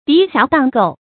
滌瑕蕩垢 注音： ㄉㄧˊ ㄒㄧㄚˊ ㄉㄤˋ ㄍㄡˋ 讀音讀法： 意思解釋： 見「滌瑕蕩穢」。